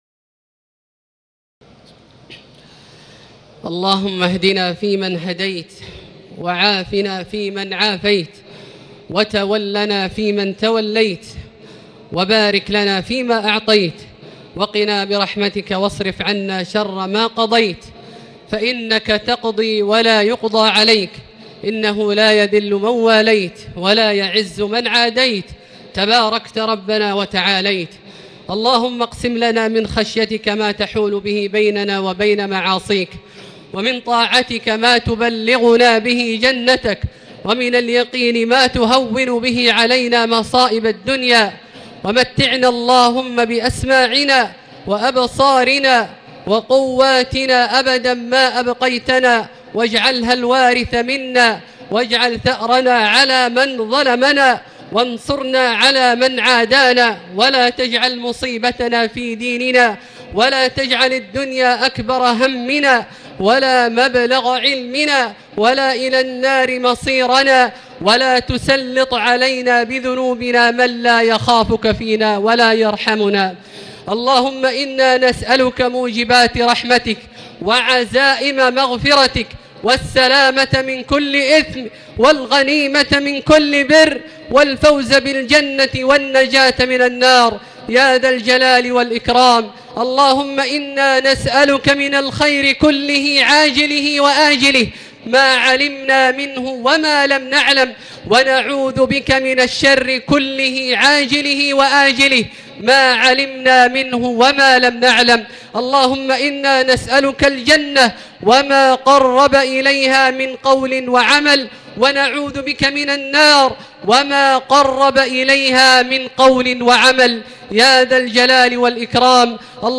دعاء القنوت ليلة 3 رمضان 1439هـ | Dua for the night of 3 Ramadan 1439H > تراويح الحرم المكي عام 1439 🕋 > التراويح - تلاوات الحرمين